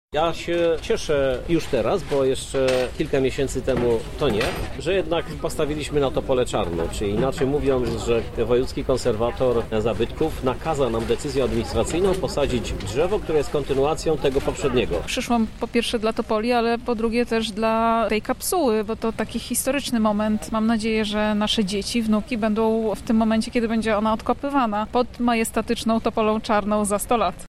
Lublinianie tłumnie zjawili się, by powitać nowego mieszkańca, a przewodził im prezydent Krzysztof Żuk: